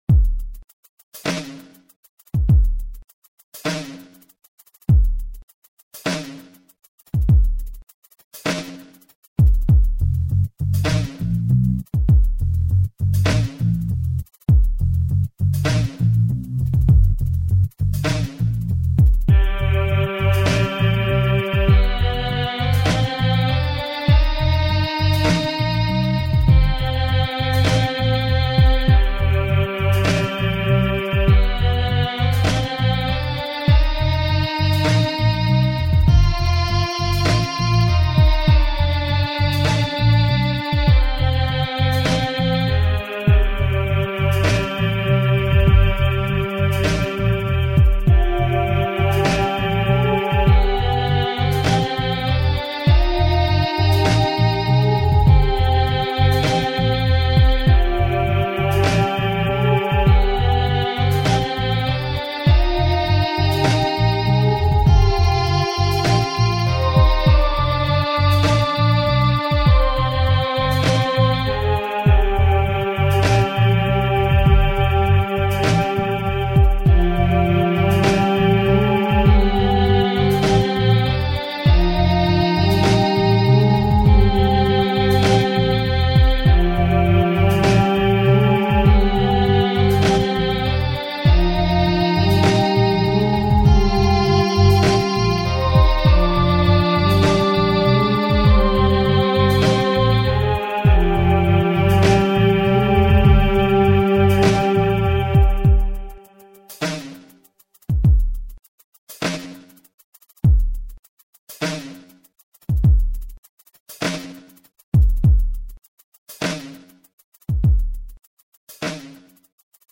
but very moody